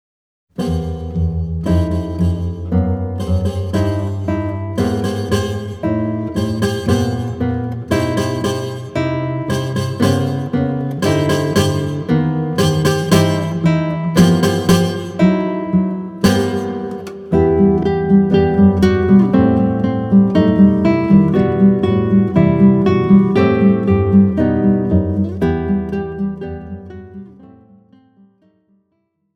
Gattung: für drei oder vier Gitarren